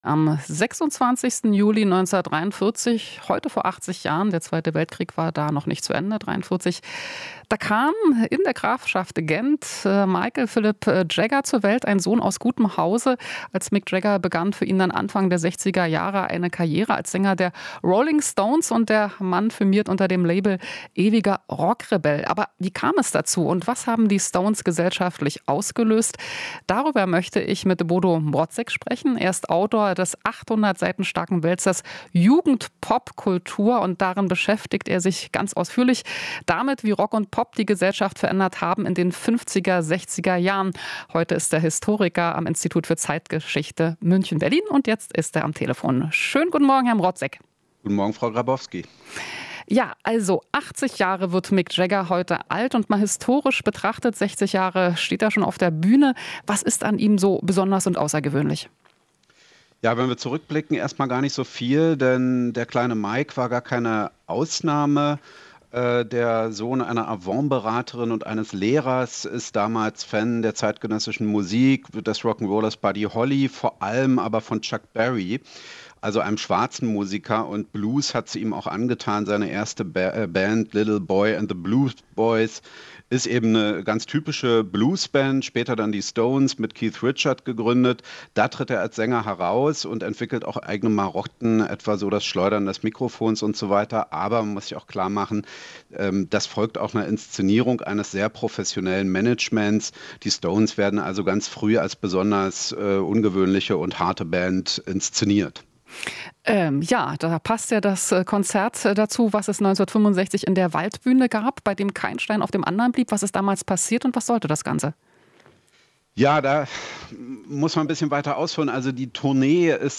Interview - Vom Blues-Fan zum Rock-Rebell: Mick Jagger wird 80